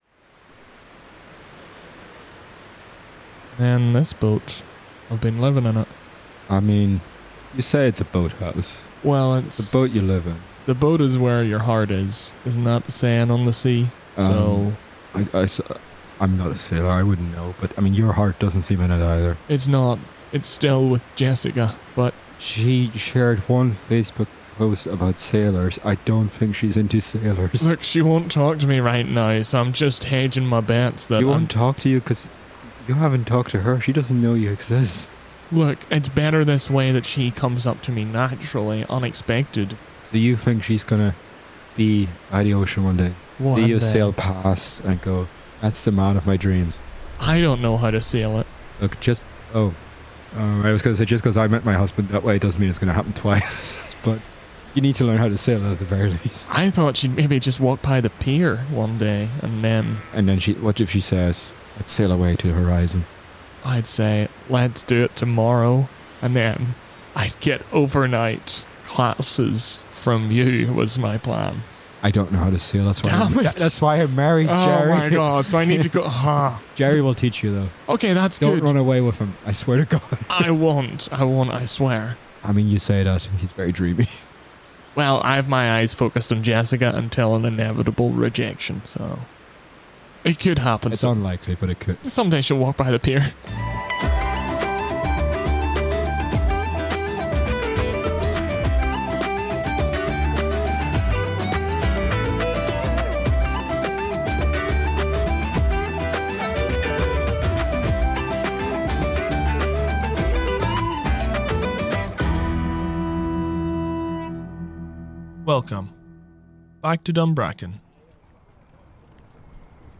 Opening and closing credits music